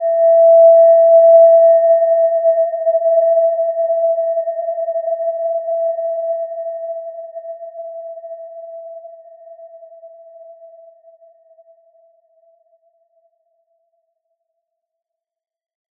Gentle-Metallic-4-E5-p.wav